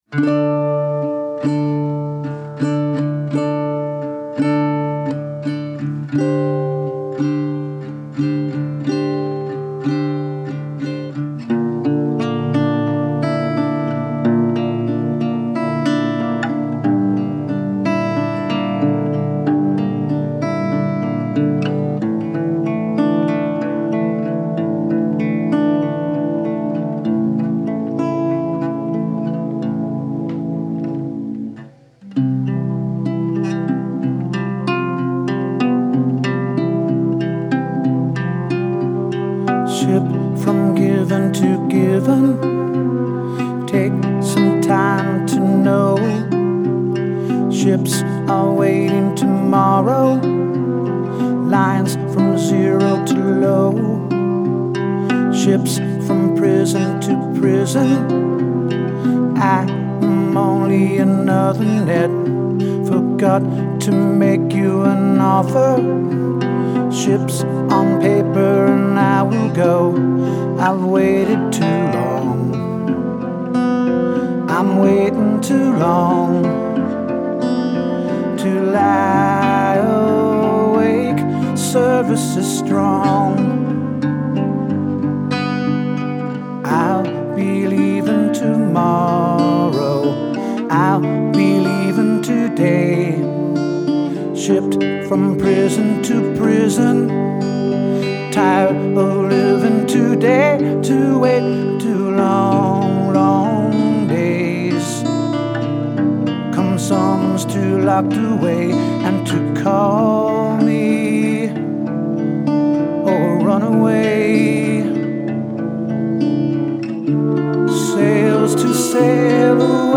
moods ranging from jubilant to melancholy to mean